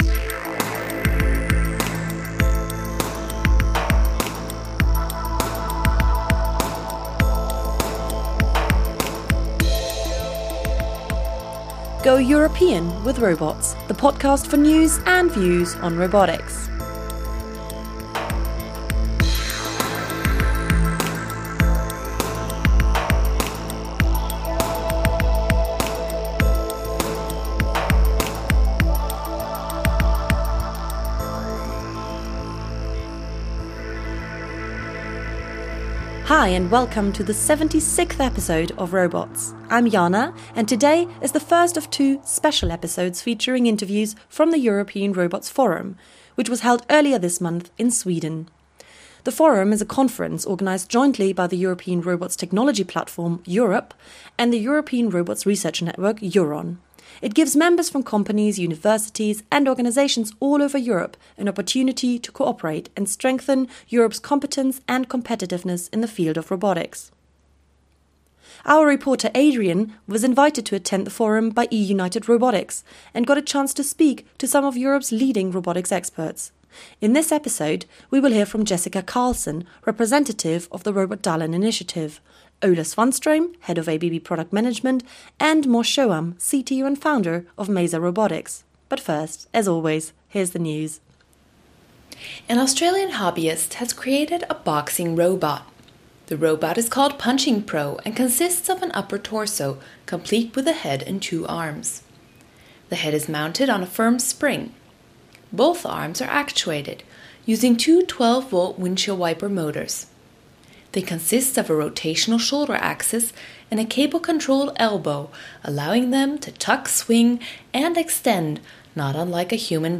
The European Robotics Forum, jointly organized by the European Robotics Technology Platform (EUROP) and the European Robotics Research Network (EURON), was hosted this year on April 6-8, in Västerås, Sweden by Robotdalen.